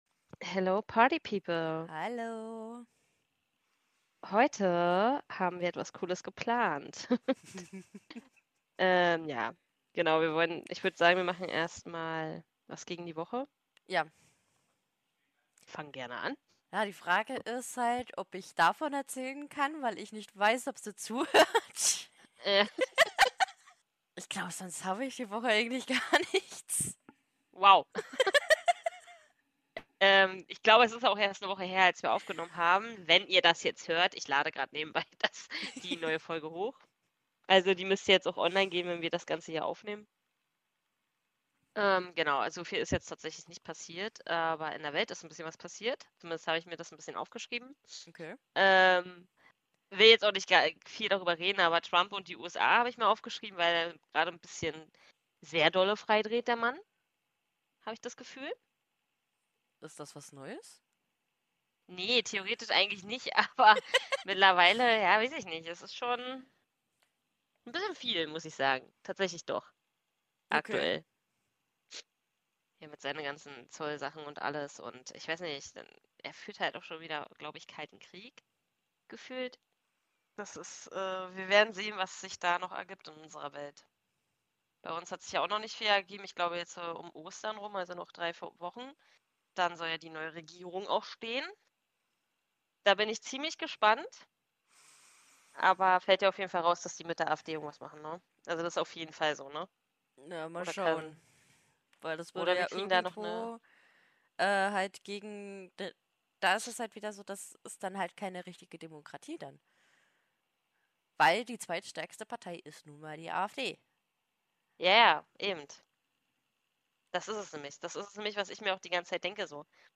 Es wird gelacht, geraten und auch mal gestaunt – denn einige Geschichten klingen zu verrückt, um wahr zu sein (Spoiler: Manche sind es trotzdem).